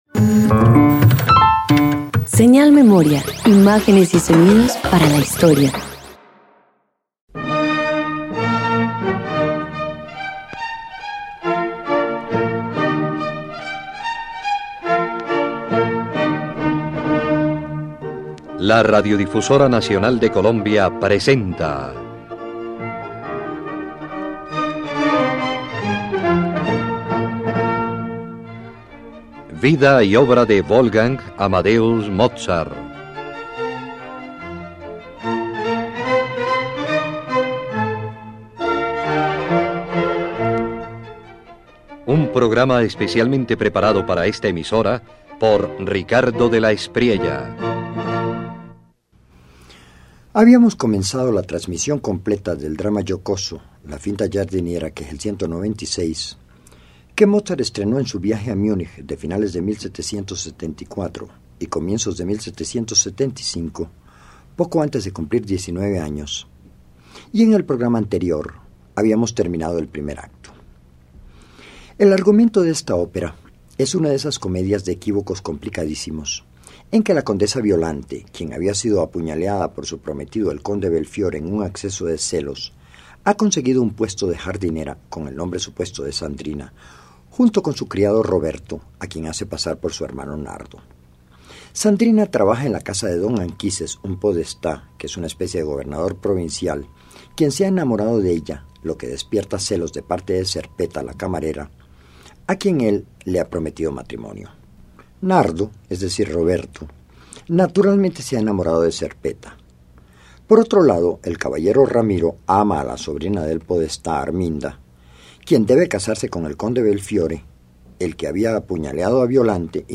En esta parte de la Finta Giardiniera, el podestá expresa su cómica frustración mientras Ramiro revela su lado más humano con el aria Dolce d’amor compagna. Wolfgang Amadeus Mozart combina humor, ternura y refinamiento orquestal.
104 Opera  Finta Giardinera Parte V_1.mp3